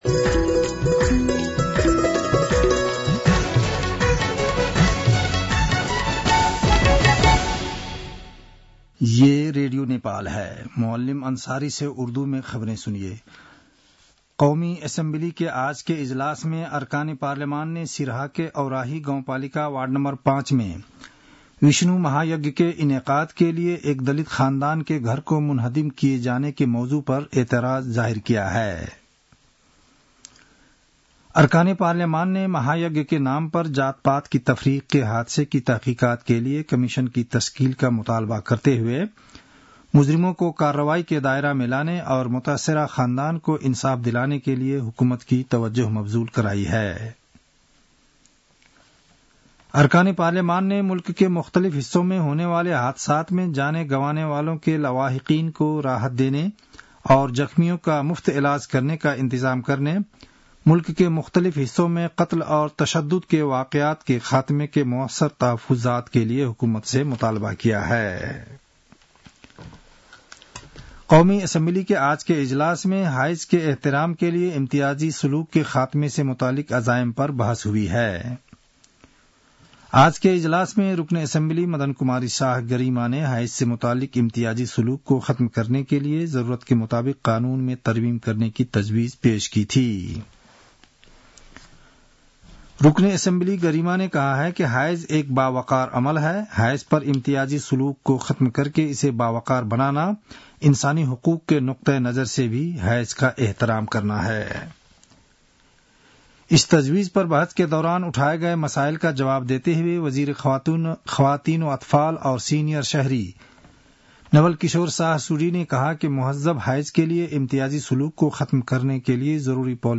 उर्दु भाषामा समाचार : ८ चैत , २०८१